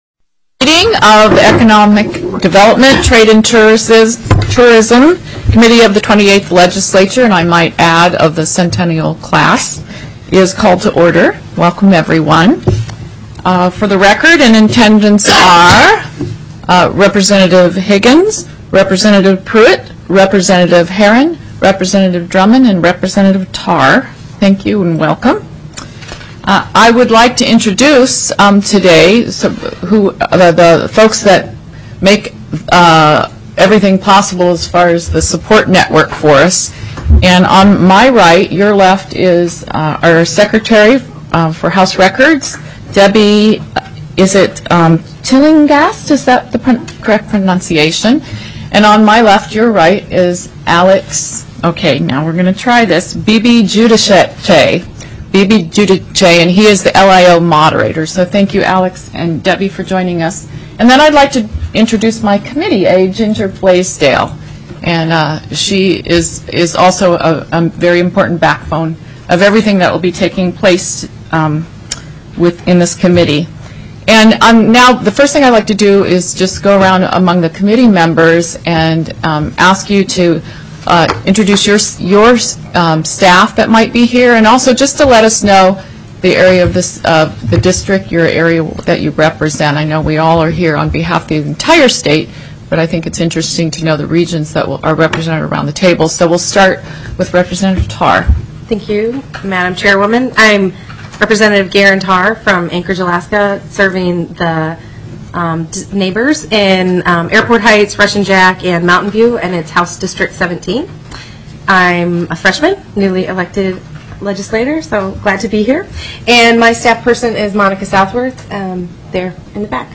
01/17/2013 10:15 AM House ECON. DEV., TRADE & TOURISM